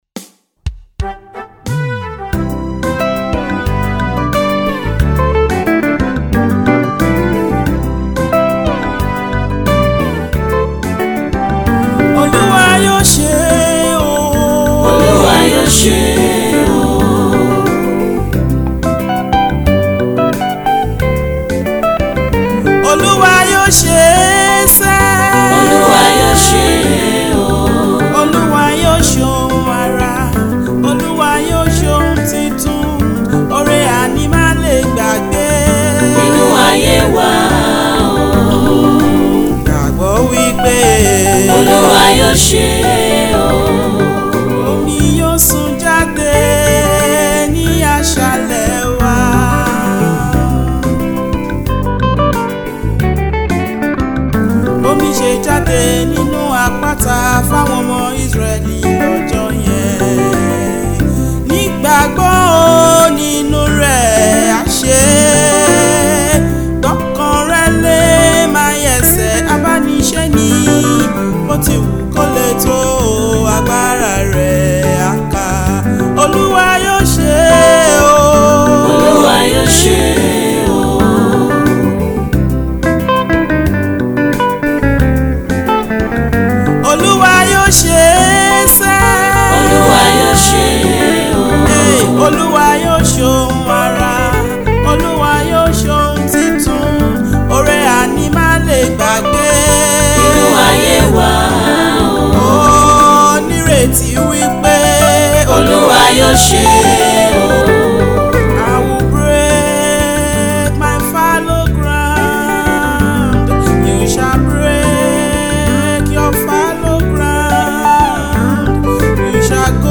Gospel music minister